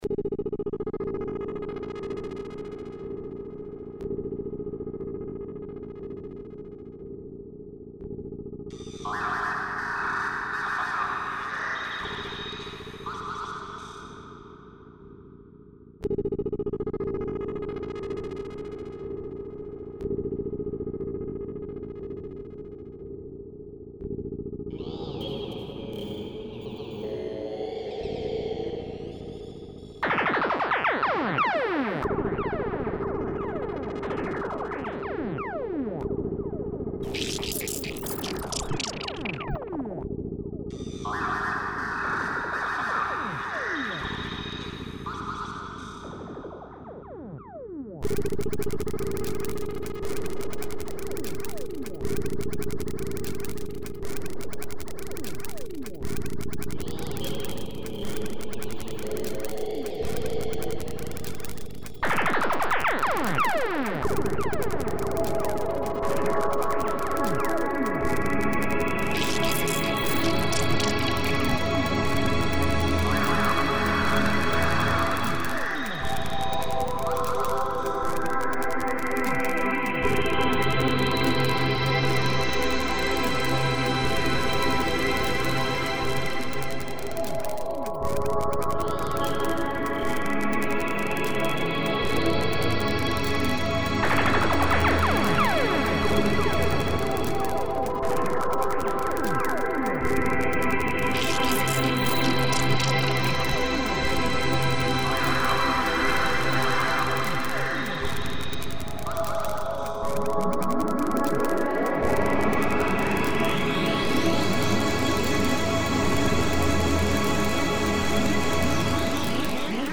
Psytrance